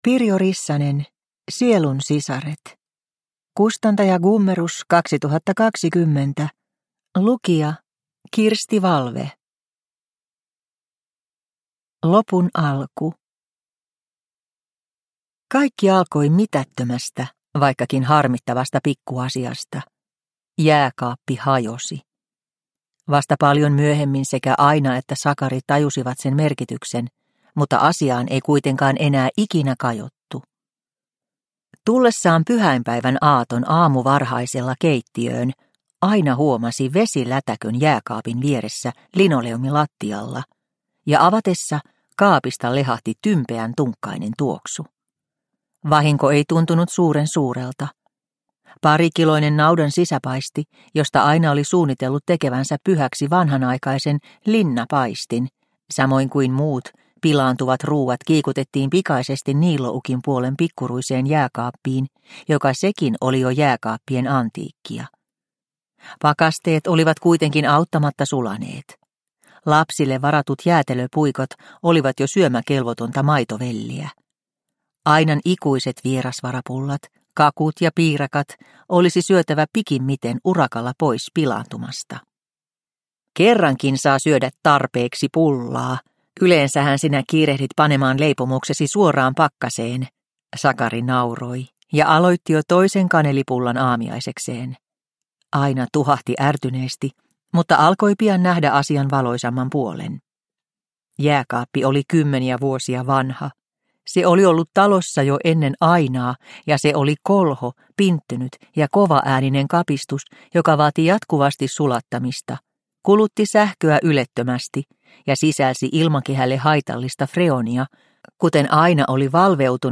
Sielunsisaret – Ljudbok – Laddas ner